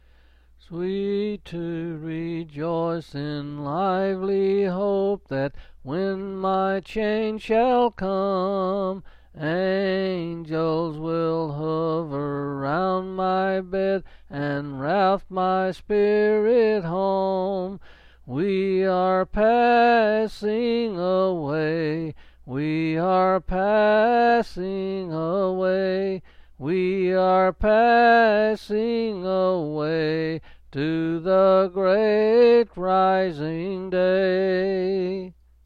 Quill Pin Selected Hymn
C. M.